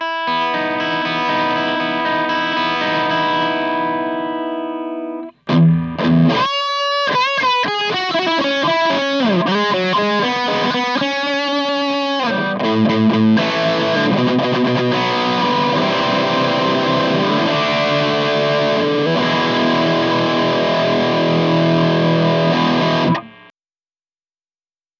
キャビネットは中古でゲットしたJetCity JCA12XSにCelestion Vintage30を搭載。マイクは15年以上前に買ったSM57。
ノイズ処理も一切無し。
フレーズは25秒で、最初のアルペジオはペダルオフでアンプサウンドになります。
レスポール+ピーヴィー、ってことでファットです！